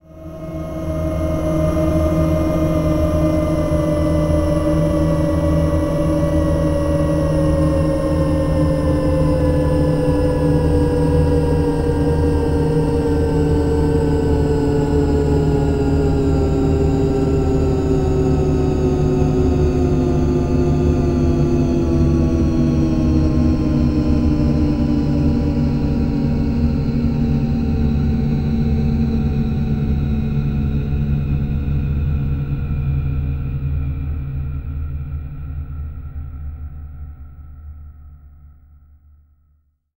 Kill A Robot; Very Slow Machine Motor Winding Down.